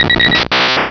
Cri de Doduo dans Pokémon Rubis et Saphir.